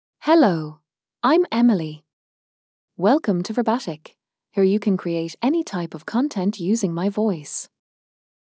FemaleEnglish (Ireland)
Emily — Female English AI voice
Emily is a female AI voice for English (Ireland).
Voice sample
Female
Emily delivers clear pronunciation with authentic Ireland English intonation, making your content sound professionally produced.